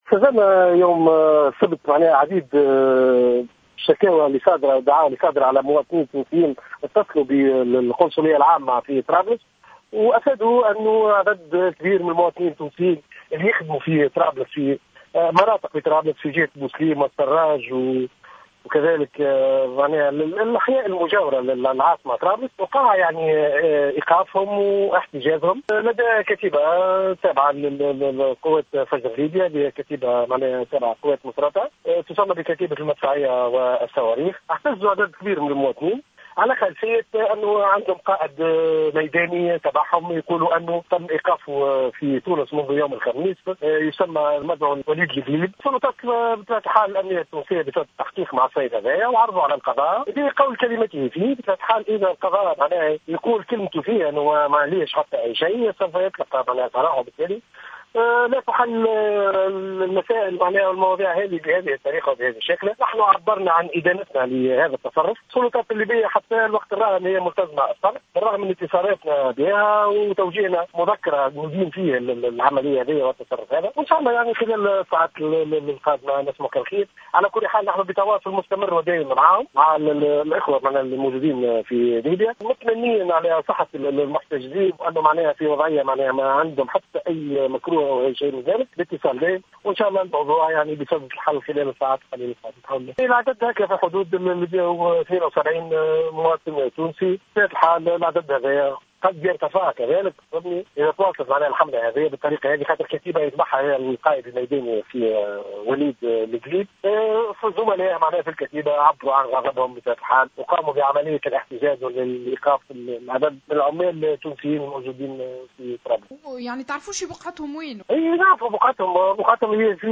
أكد القنصل العام التونسي في ليبيا ابراهيم الرزقي في تصريح لجوهرة "اف ام" إن مليشيات مسلحة تابعة لقوات فجر ليبيا تسمى بكتيبة المدفعية والصواريخ قامت أمس السبت بإحتجاز حوالي 172 عاملا تونسيا واقتيادهم الى مقر بطرابلس وذلك ردا على إحتجاز ليبي تابع لقوات فجر ليبيا في أحد المطارات التونسية .